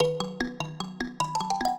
mbira
minuet8-8.wav